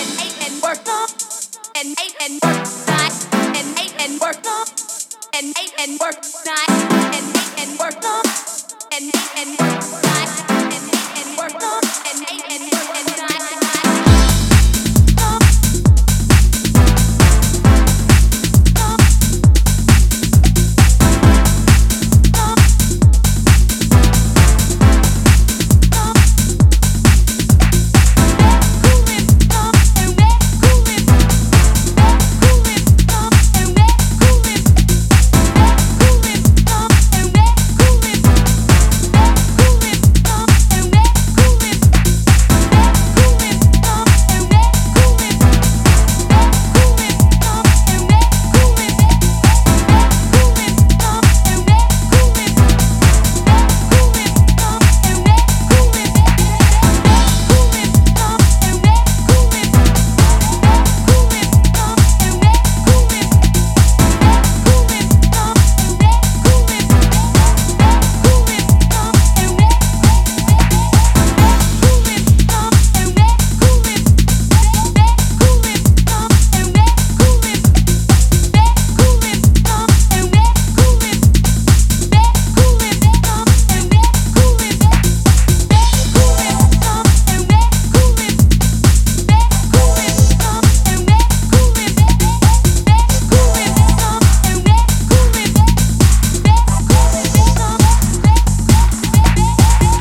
energetic and raw club-oriented EP